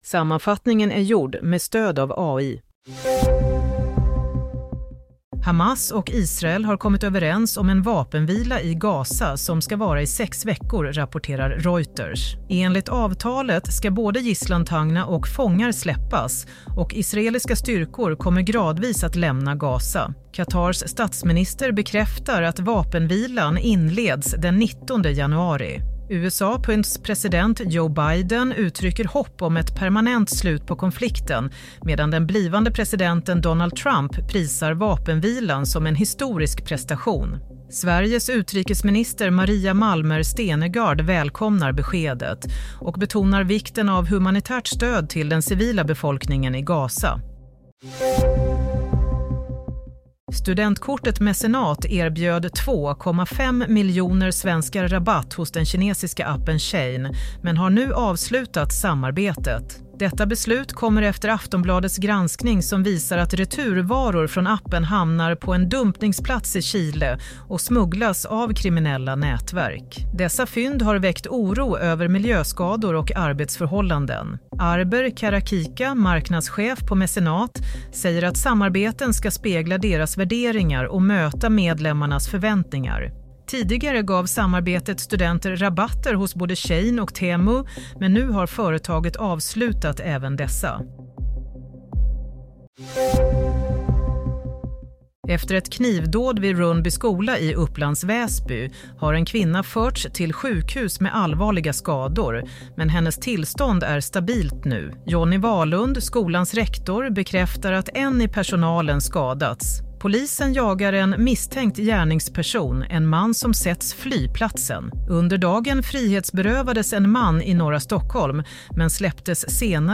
Nyhetssammanfattning – 15 januari 22:00
Sammanfattningen av följande nyheter är gjord med stöd av AI. - Hamas och Israel är överens om vapenvila i Gaza - Efter Aftonbladets granskning – företaget bryter med Shein - Kvinna skadad i knivdåd vid skola